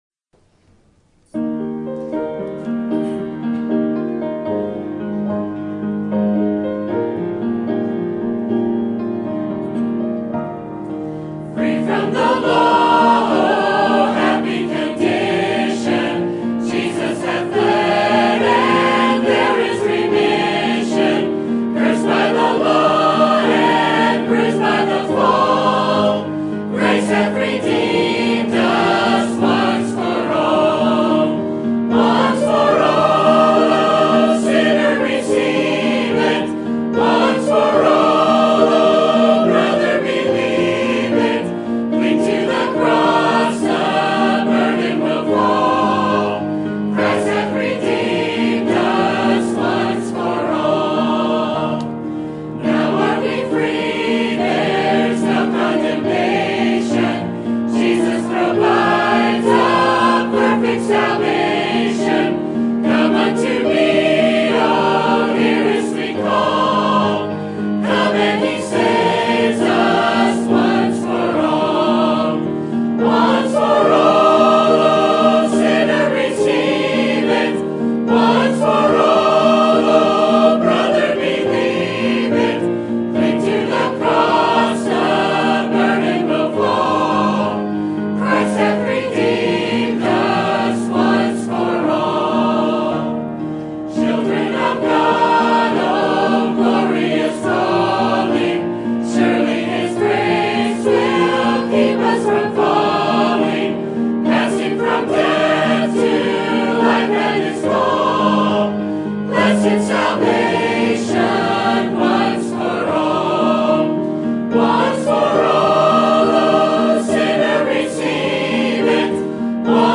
Sermon Topic: Life of Kings and Prophets Sermon Type: Series Sermon Audio: Sermon download: Download (30.24 MB) Sermon Tags: 2 Kings Kings Prophets Judgment